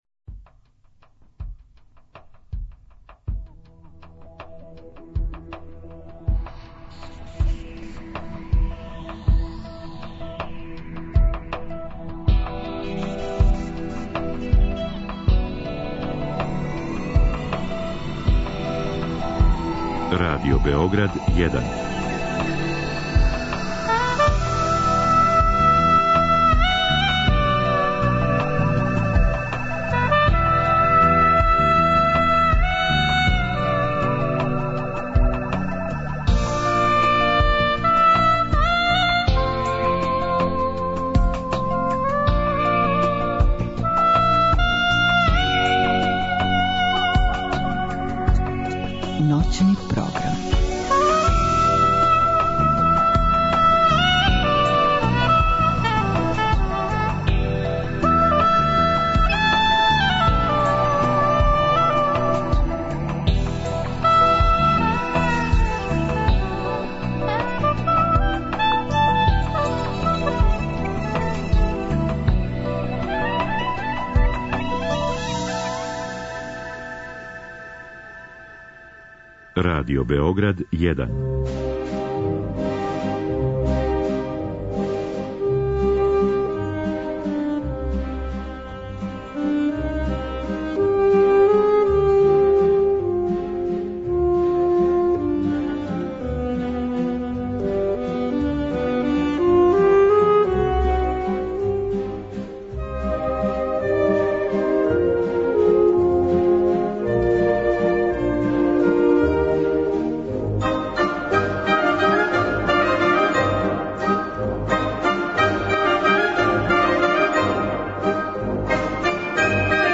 Овога четвртка, од поноћи до 02, гости у студију Радио Београда 1 биће чланови камерног састава 'Trio Classica Aeterna'